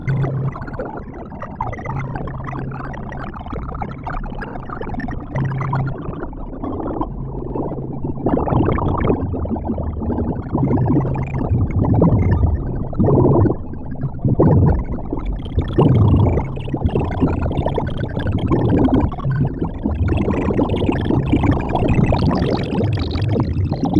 runoff_water.mp3